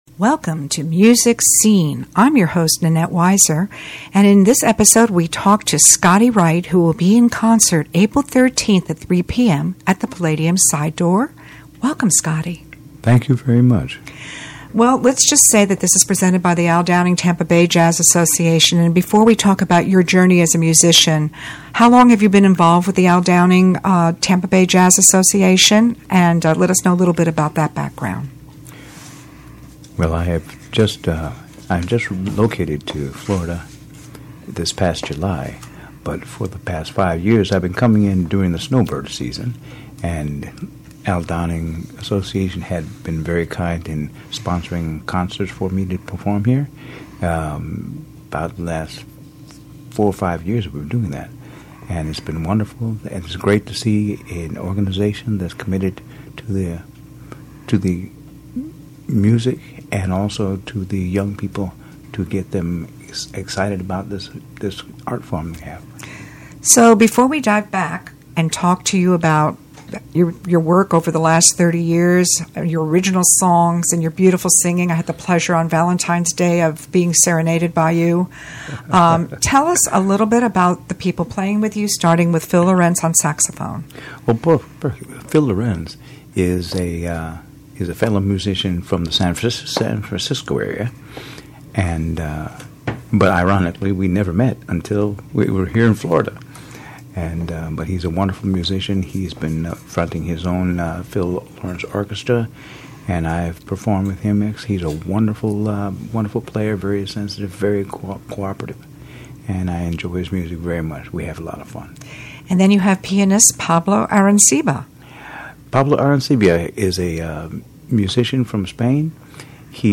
In this episode of MUSIC SCENE, host